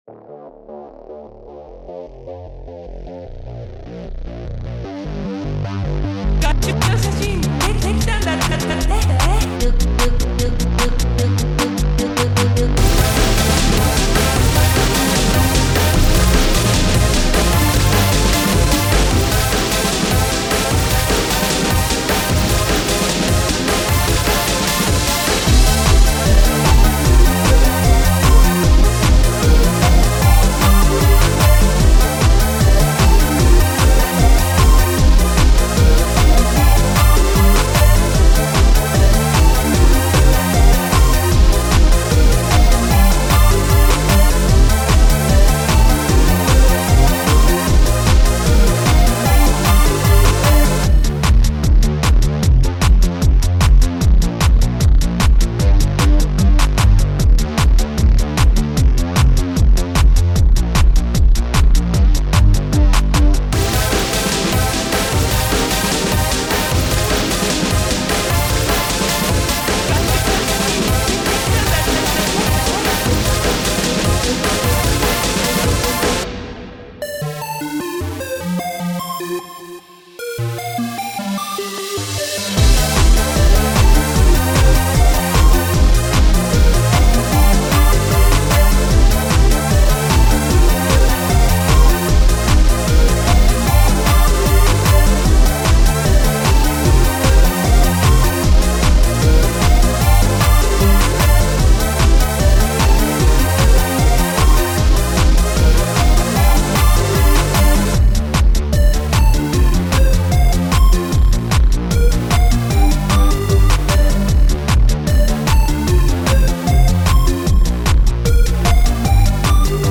slowed